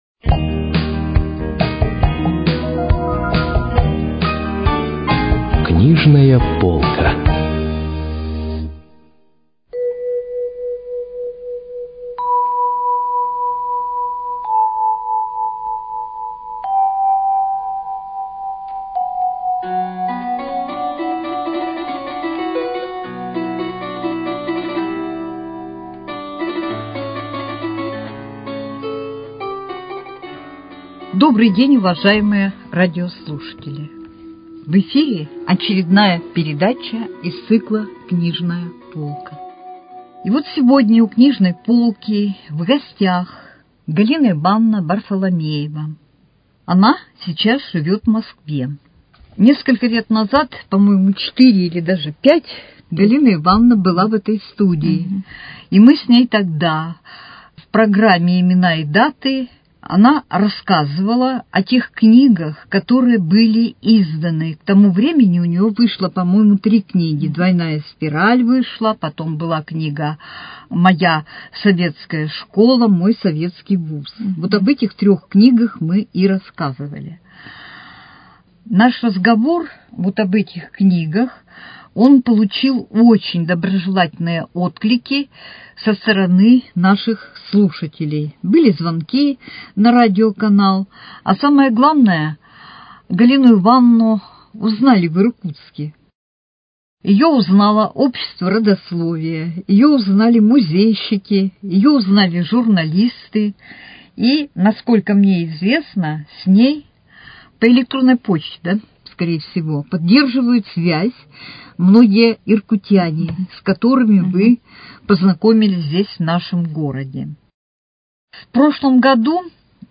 Книжная полка: Беседа